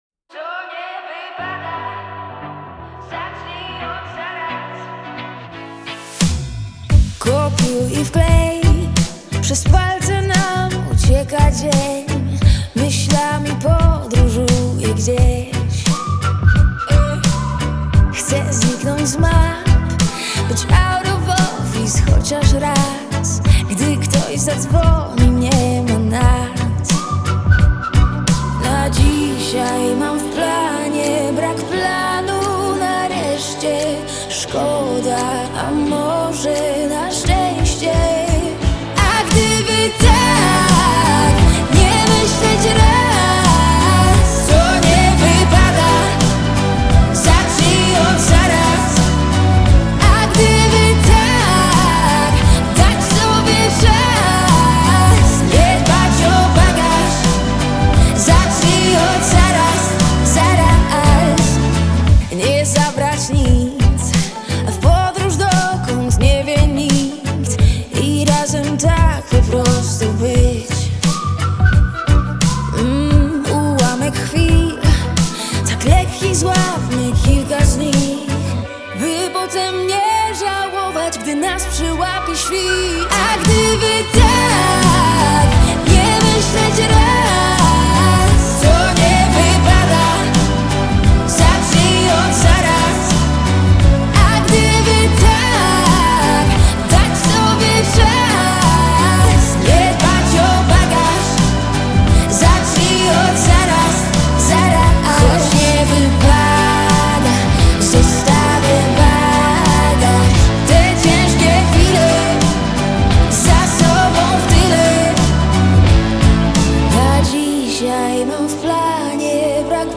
современная поп-песня